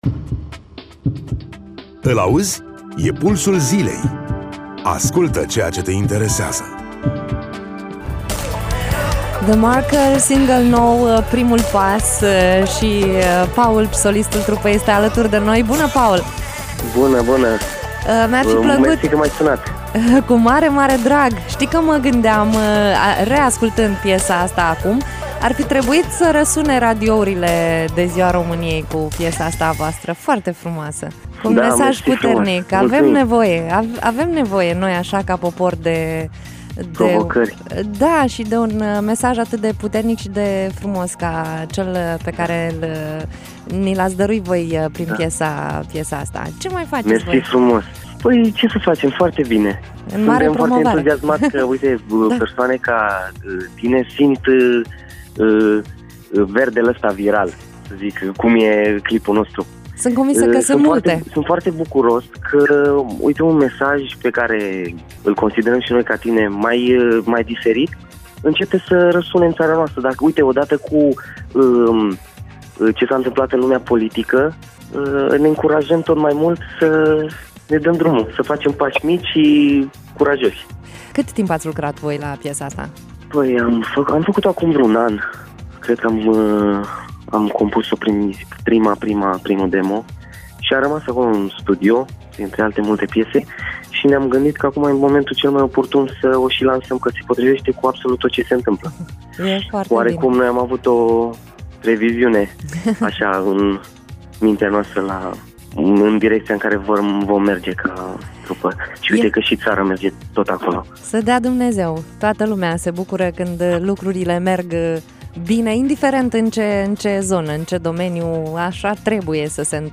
Interviu-The-Marker.mp3